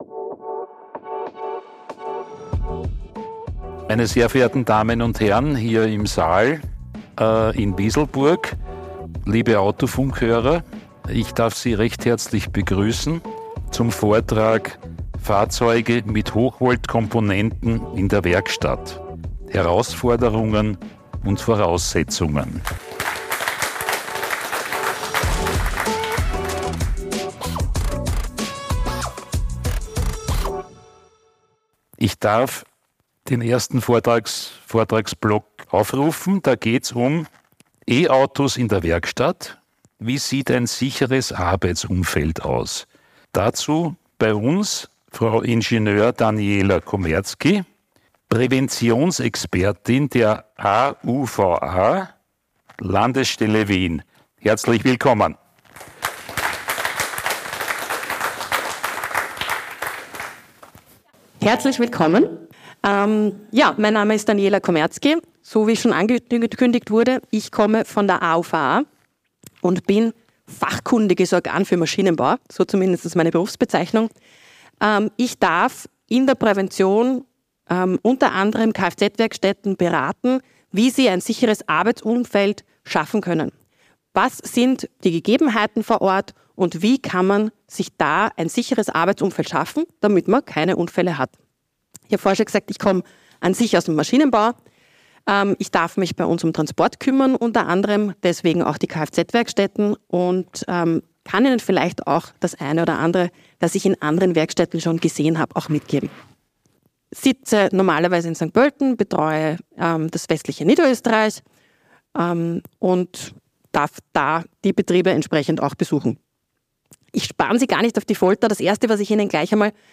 Autofunk LIVE – Fahrzeuge mit Hochvolt-Komponenten in der Werkstatt – Herausforderungen und Voraussetzungen ~ Autofunk Podcast
Beschreibung vor 1 Jahr Im Rahmen der KLS-Fachtage in Wieselburg stellten Fachleute drei zentrale Themen in den Mittelpunkt.